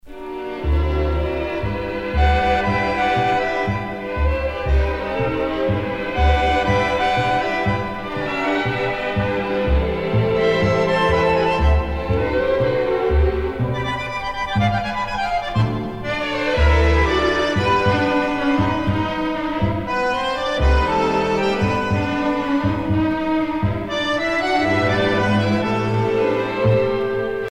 danse : tango